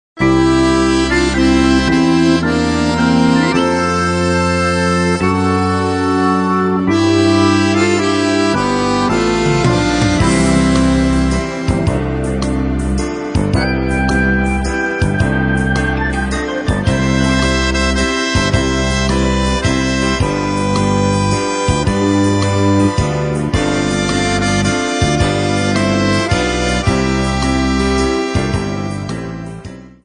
Besetzung: Akkordeon mit CD